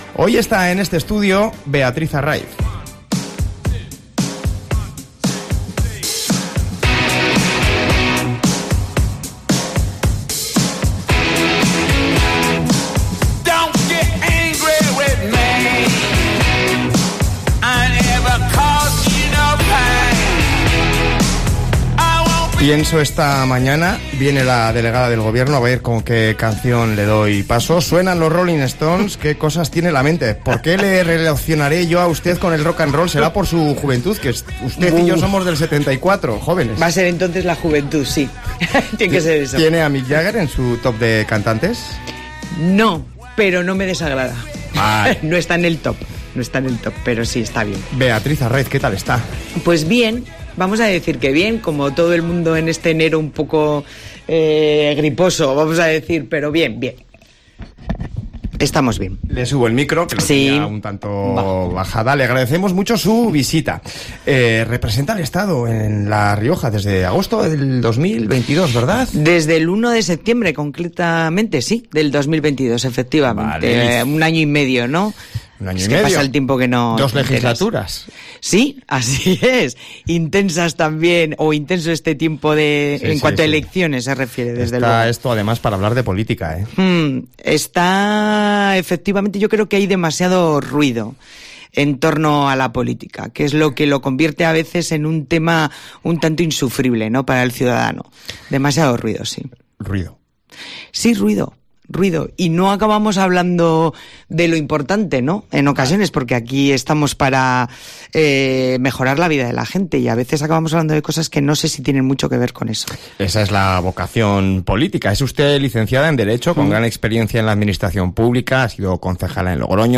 En una entrevista en COPE Rioja, la Delegada del Gobierno en La Rioja, Beatriz Arraiz, destaca los avances y retos que enfrenta la región desde su nombramiento en agosto de 2022.